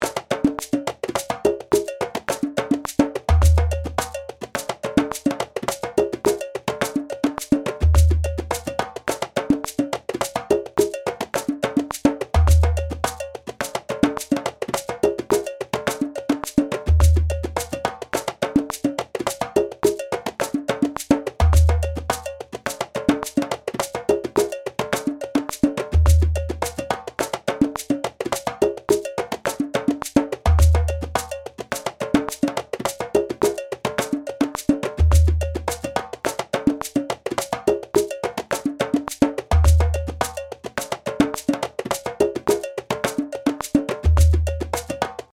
base-lez-35-106bpm.mp3